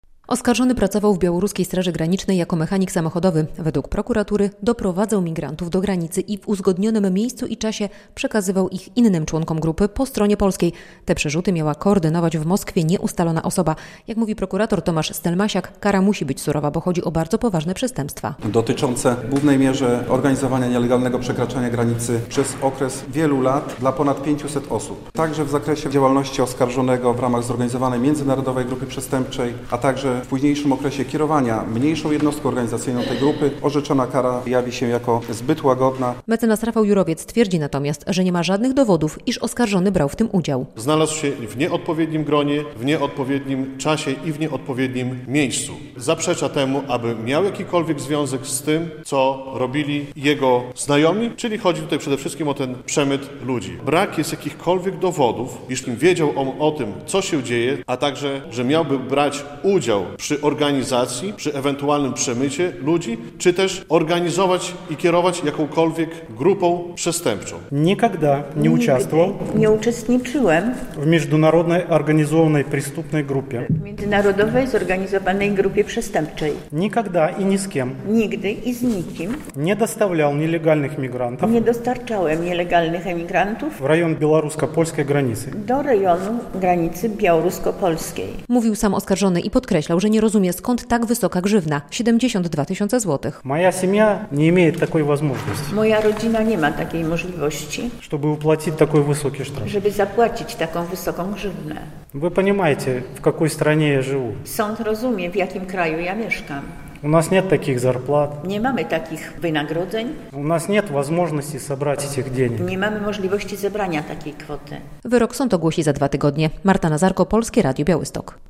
Białostocki sąd zajmuje się sprawą Białorusina oskarżonego ws. grupy organizującej przerzut Azjatów przez granicę - relacja